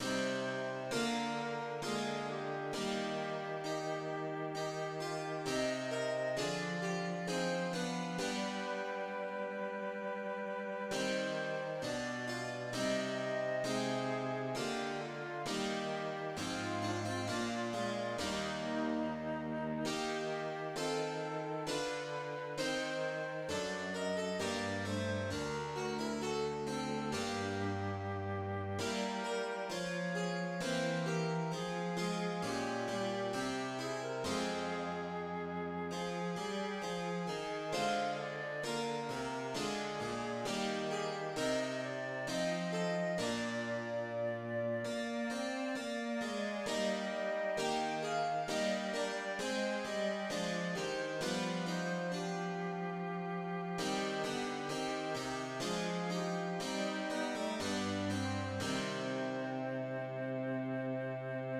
"Komm, süßer Tod, komm selge Ruh" (Come, sweet death, come, blessed rest) is a song for solo voice and basso continuo from the 69 Sacred Songs and Arias that Johann Sebastian Bach contributed to Musicalisches Gesang-Buch by Georg Christian Schemelli (BWV 478), edited by Schemelli in 1736.[1] The text is by an anonymous author.[2] Bach, by means of melody and harmony, expresses the desire for death and heaven.